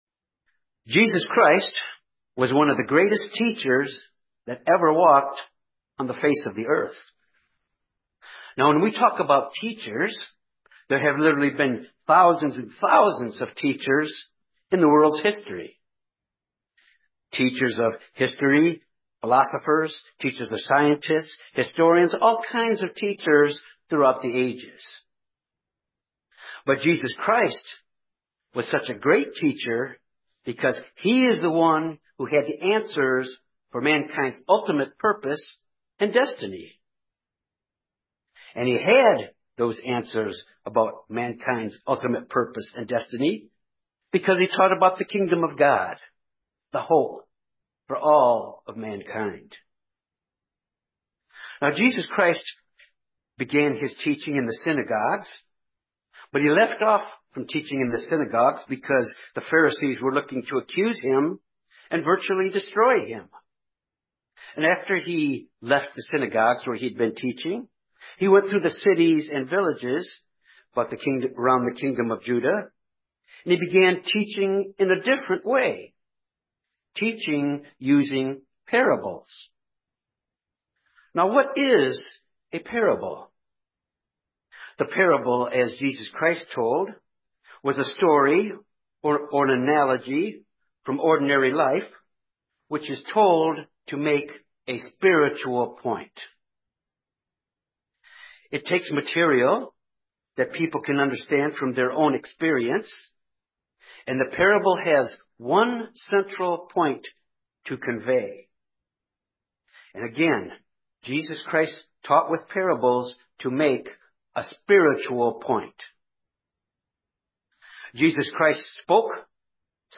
This sermon examines the significances of the soils in the Parable of the sower and the seed as they pertain to our spiritual growth. It asks what kind of soil are we growing and where we fit in this parable.